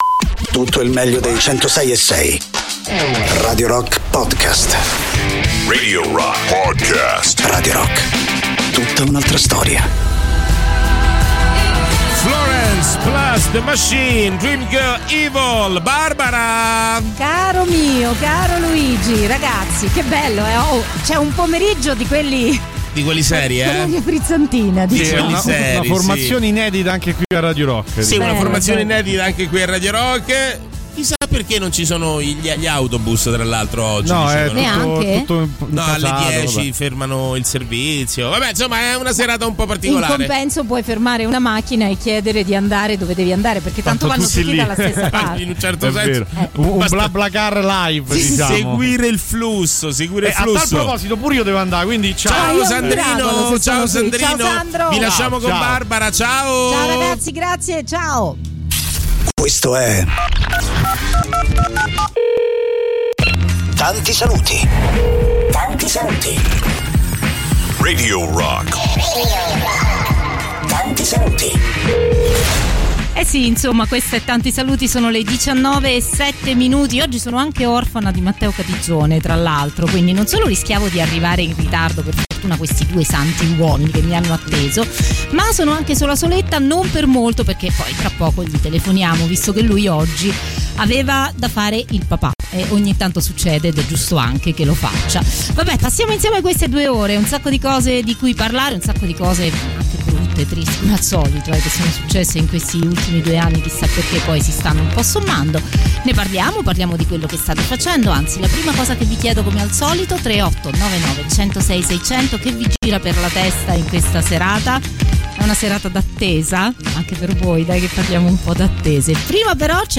in diretta dal lunedì al venerdì, dalle 19 alle 21